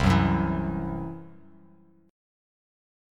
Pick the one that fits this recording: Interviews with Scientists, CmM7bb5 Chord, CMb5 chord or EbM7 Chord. CMb5 chord